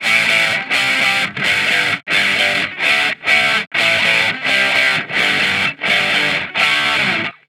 Guitar Licks 130BPM (18).wav